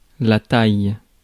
Ääntäminen
France (Paris): IPA: [la taj]